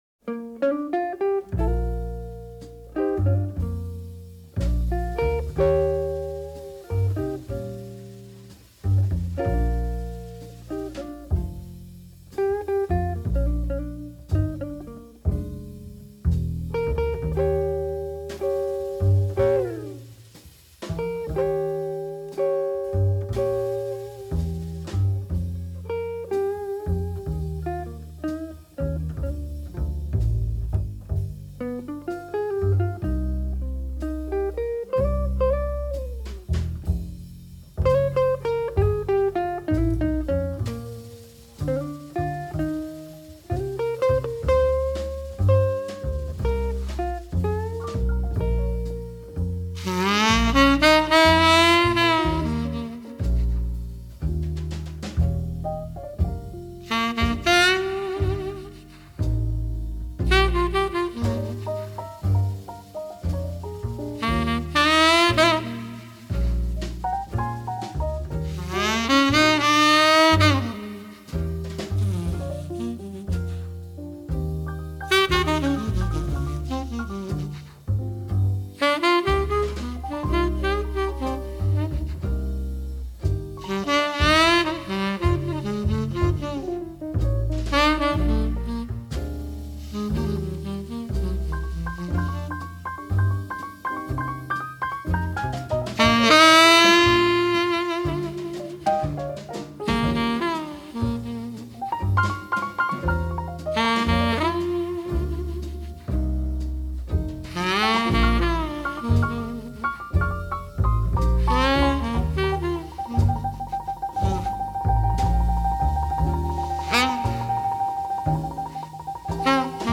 爵士及藍調 (573)
★ 如清流般柔和傾吐，撫慰人心的薩克斯風演奏！